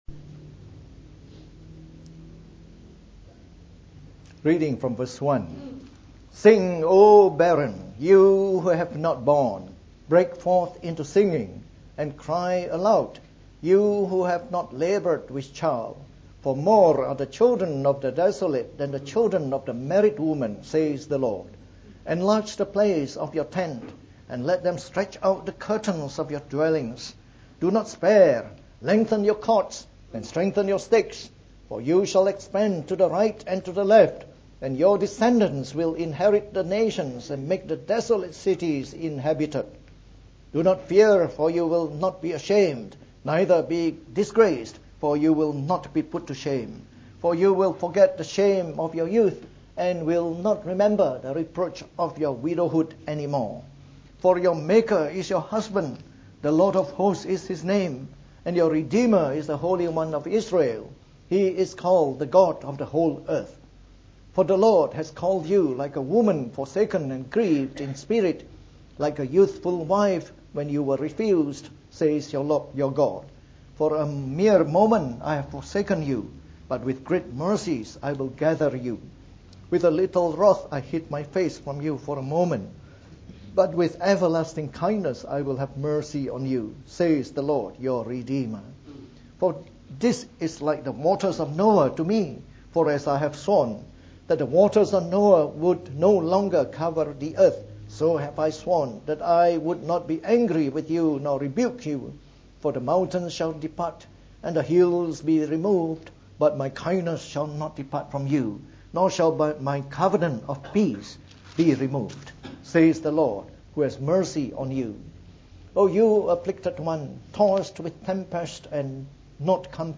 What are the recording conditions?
From our series on the Book of Isaiah delivered in the Morning Service.